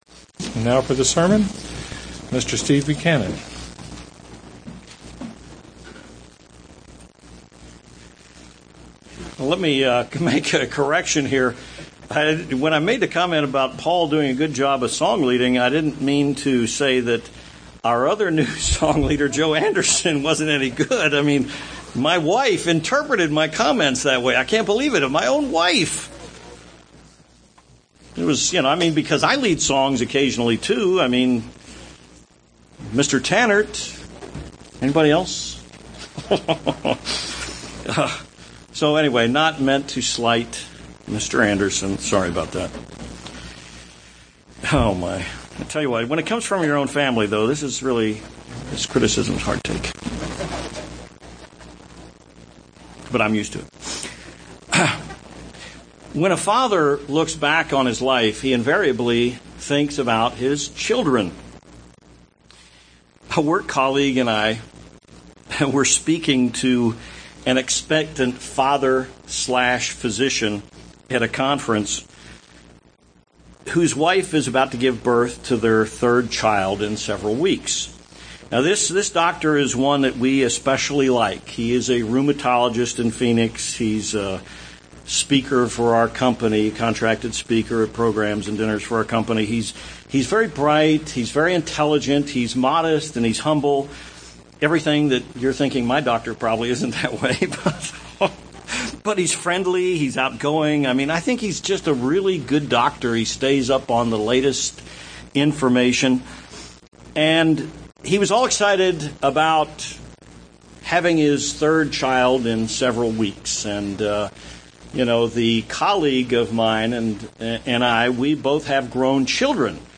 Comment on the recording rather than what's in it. Given in Tucson, AZ